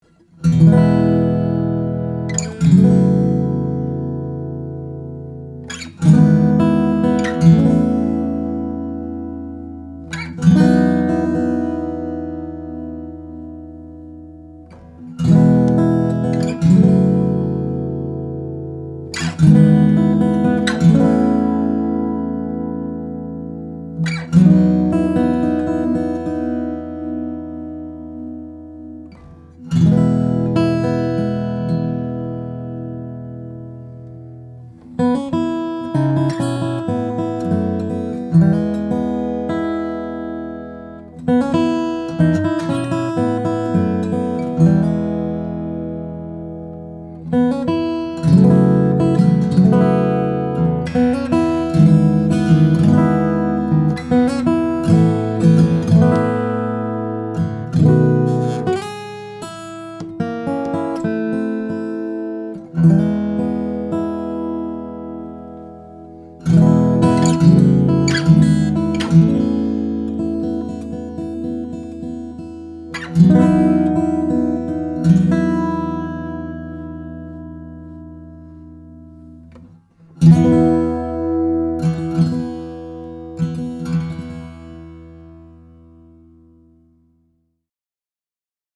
A sweet blend of darkness and clarity, liquid trebles and articulate midrange, glassy playability—these are the first things you’ll notice when you pick up this 2007 Petros Grand Concert. This is a fingerstylist’s dream machine, with a wide fretboard and clean note separation, and it’s ready to take onstage with an L.R. Baggs Anthem SL… Read more »